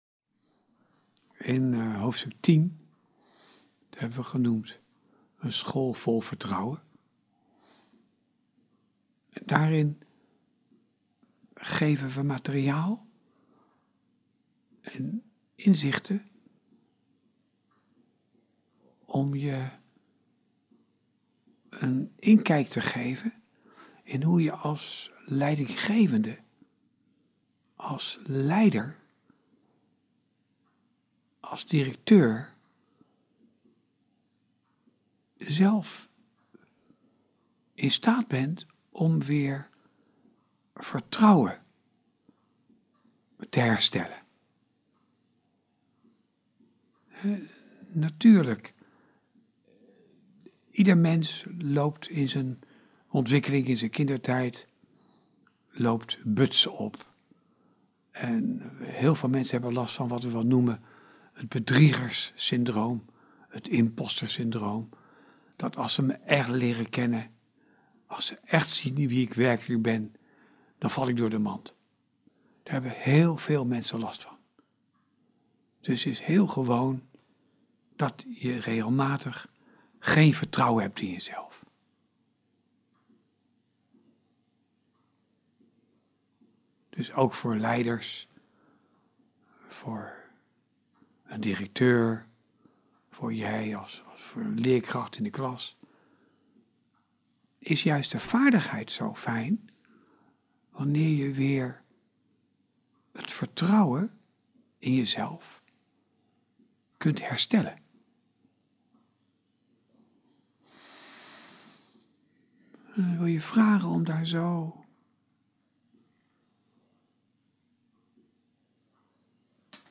meditatie bij hoofdstuk 10 (school vol vertrouwen)